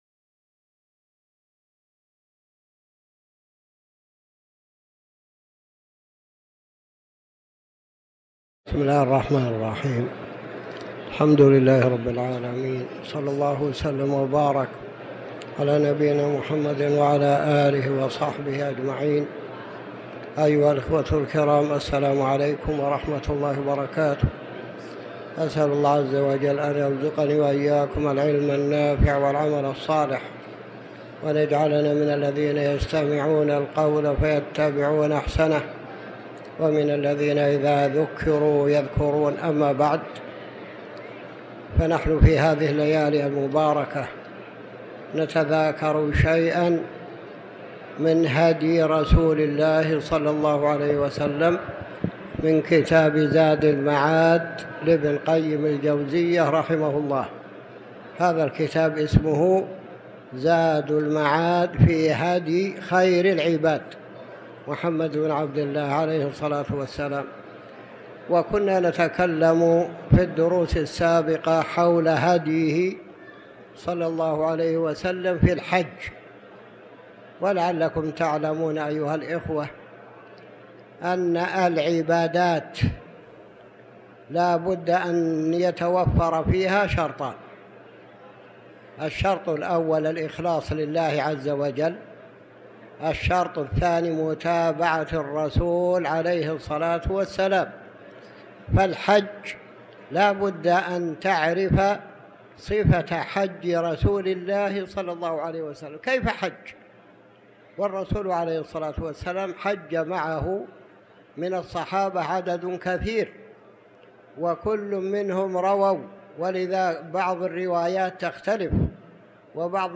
تاريخ النشر ٧ جمادى الأولى ١٤٤٠ هـ المكان: المسجد الحرام الشيخ